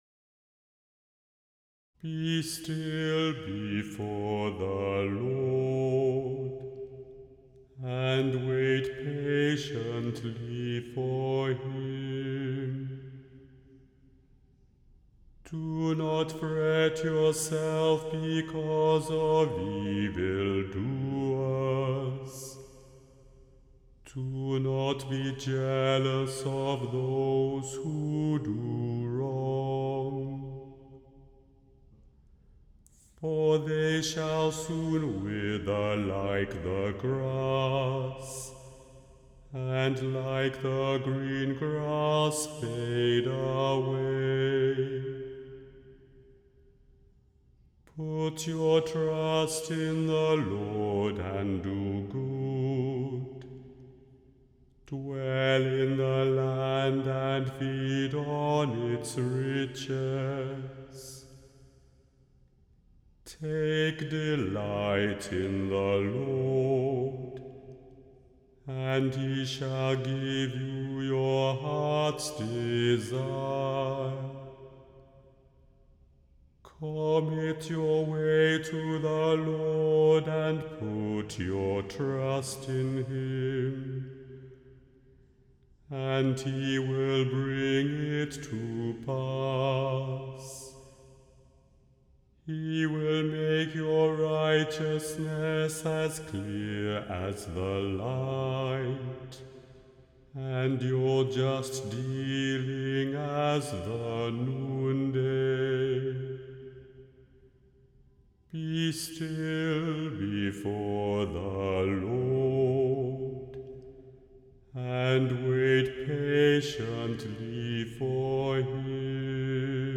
The Chant Project – Chant for Today (June 22) – Psalm 37 vs1-7